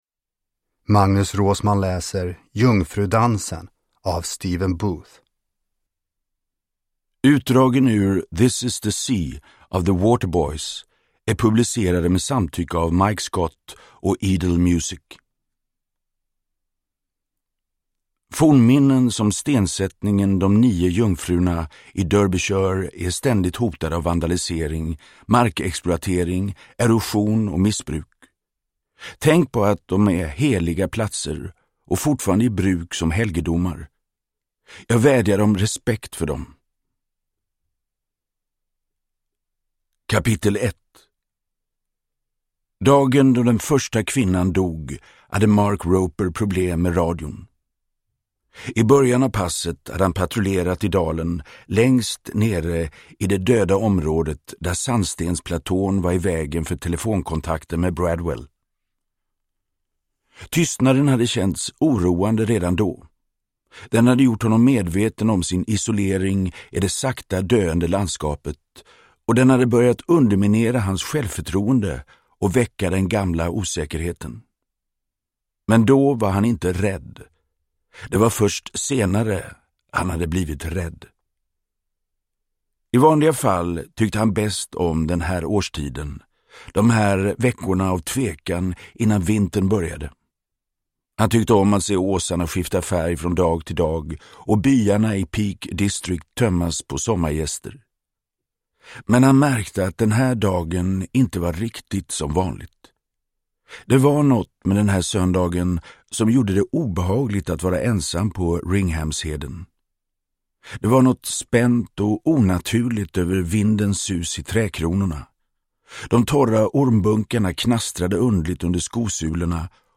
Jungfrudansen – Ljudbok – Laddas ner
Uppläsare: Magnus Roosmann